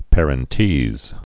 (pârən-tēz, -tēs, păr-)